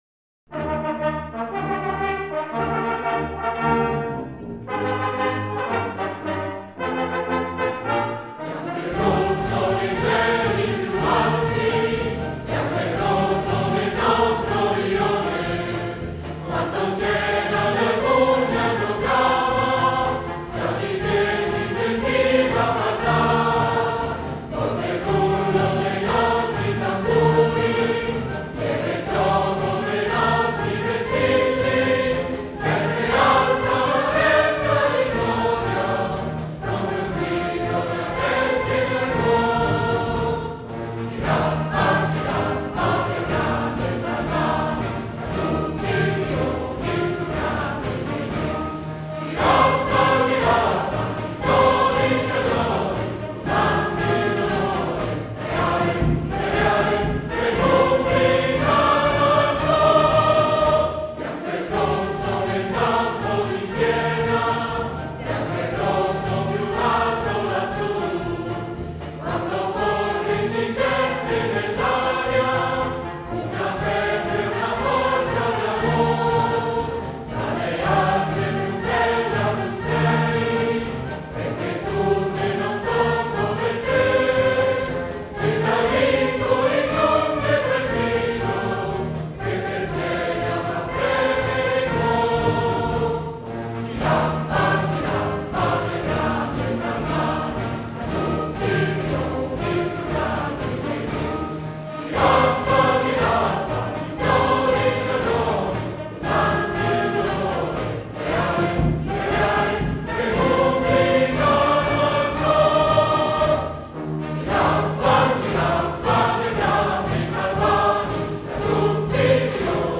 Versi di Bruno Tanganelli, musica del Maestro Nino Oliviero - L’inno è un “marciabile” in 2/4, essenziale e musicalmente ben equilibrato, con un crescendo finale ad effetto.